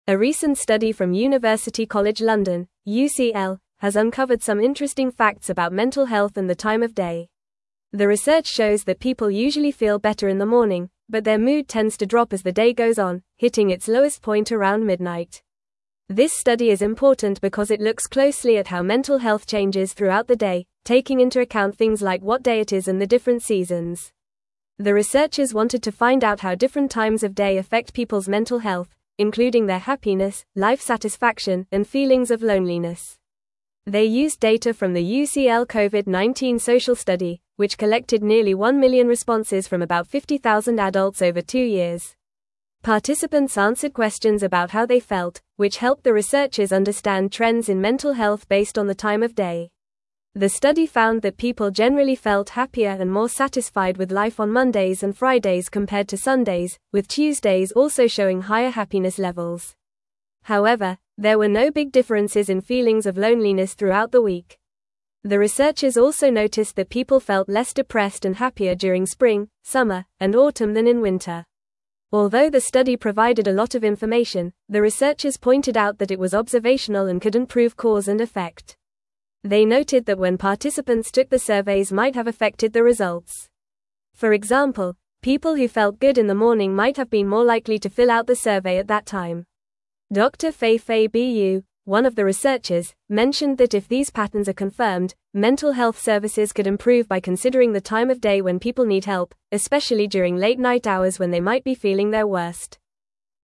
Fast
English-Newsroom-Upper-Intermediate-FAST-Reading-Mental-Health-Declines-Throughout-the-Day-Study-Finds.mp3